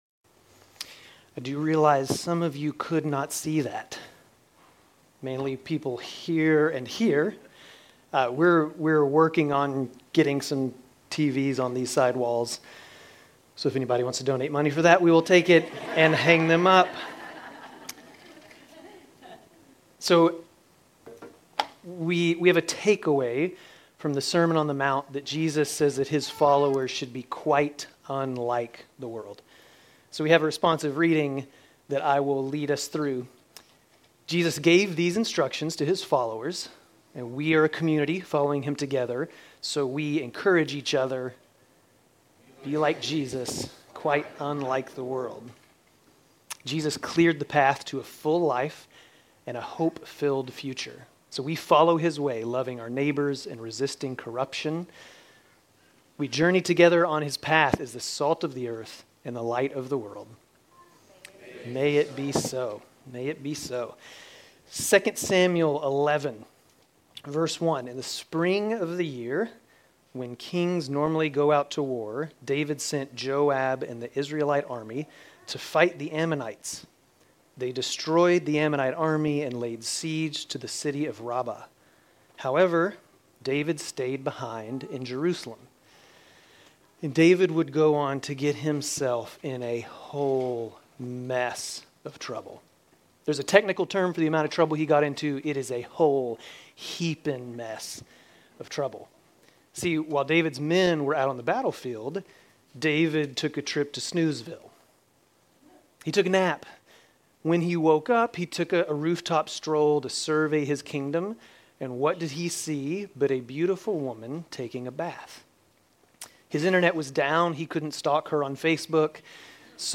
Grace Community Church Dover Campus Sermons 3_23 Dover Campus Mar 24 2025 | 00:26:23 1x 00:00 / 00:26:23 Subscribe Share RSS Feed Share Link Embed